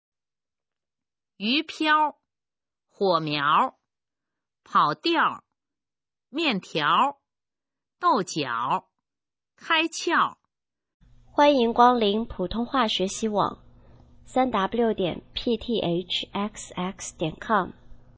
普通话水平测试用儿化词语表示范读音第20部分